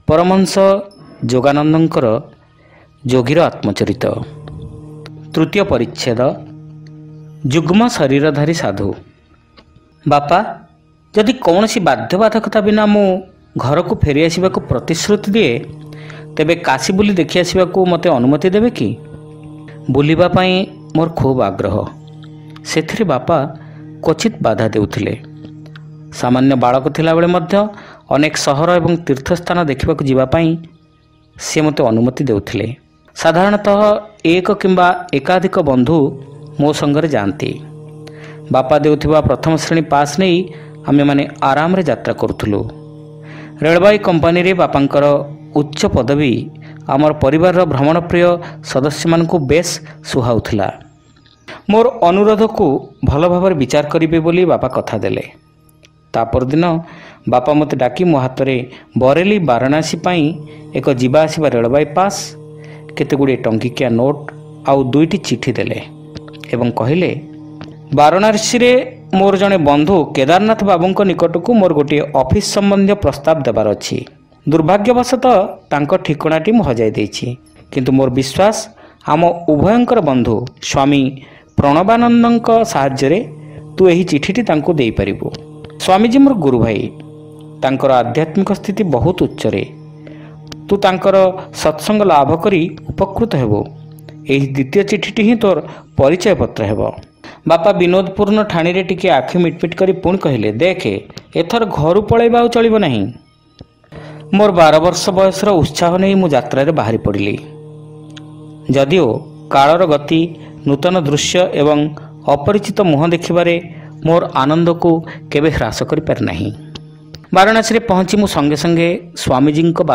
Audio Story : Jugmasariradhari Sadhu - Yogira Atmacharita